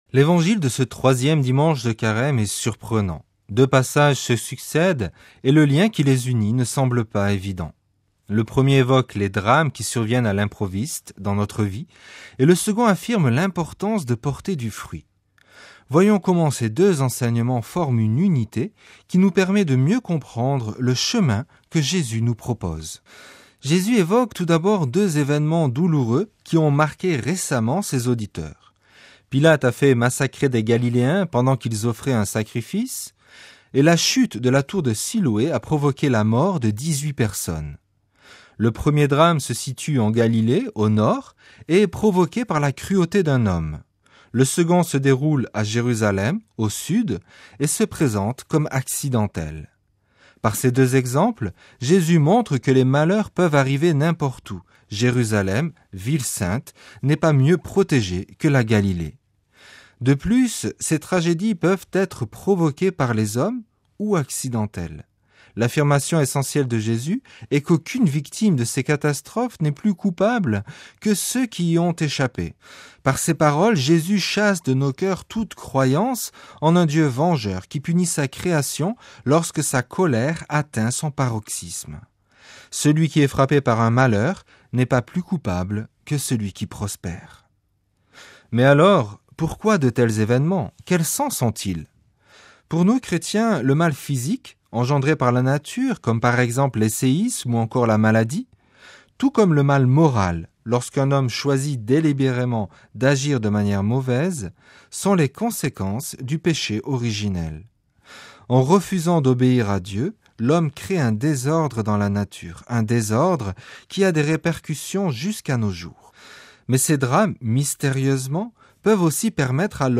Commentaire de l'Evangile du dimanche 3 mars